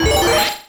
snd_speedup.wav